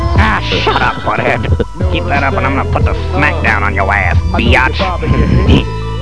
Beavis sounds upset he can't watch t.v.